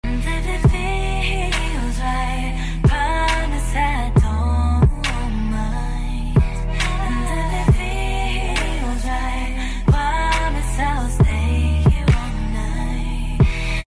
Kitty meow meow goes pur sound effects free download